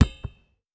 Field Goal Kick Goalpost.wav